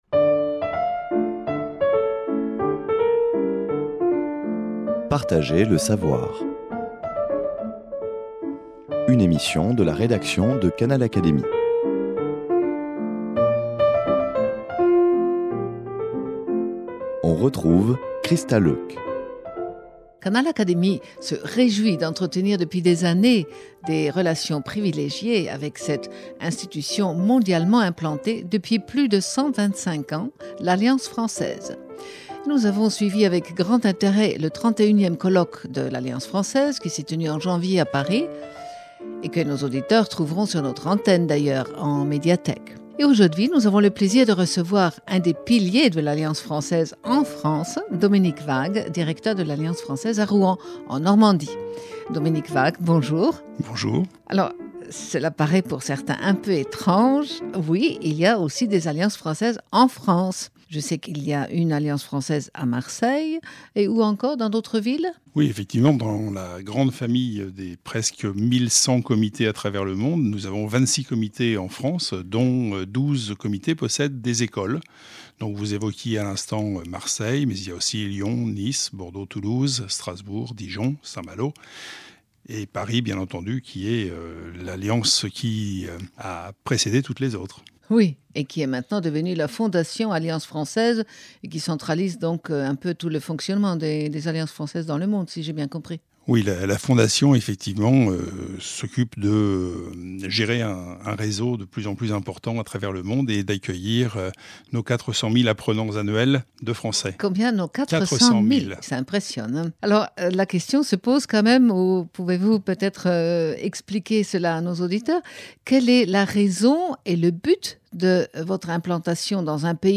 L’entretien s'engage autour d'une « carte postale » de Normandie, son histoire, ses sites touristiques, son économie, ses habitants, sa terre et ses lieux qui ont toujours inspiré les artistes — ne serait-ce que la Cathédrale de Rouen peinte une quarantaine de fois par Monet !